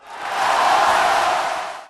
cheerShort.wav